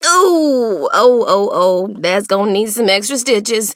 BS_juju_hurt_vo_05.mp3